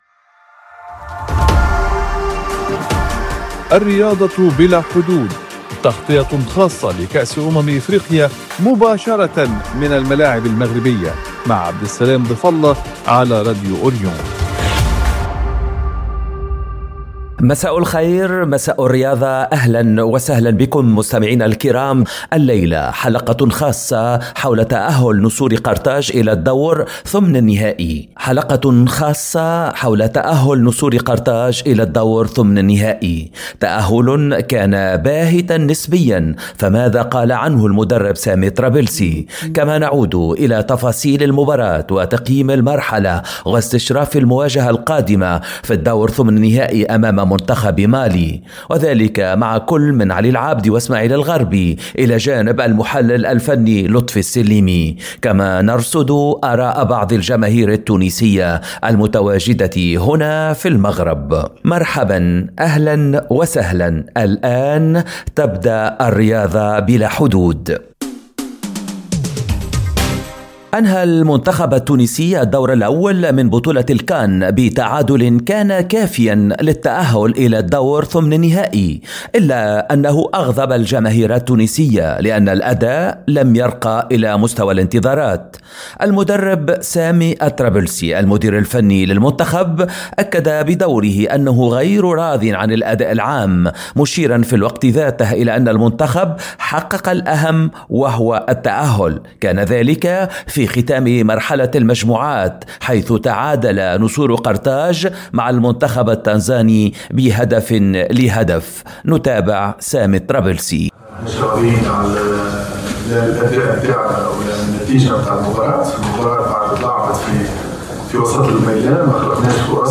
كما نرصد آراء بعض الجماهير التونسية المتواجدة هنا في المغرب.